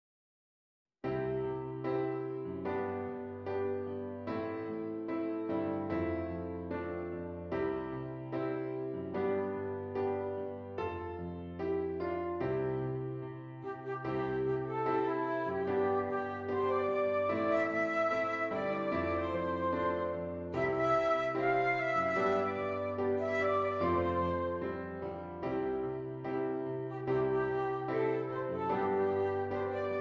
Flute Solo with Piano Accompaniment
Does Not Contain Lyrics
C Major
Lento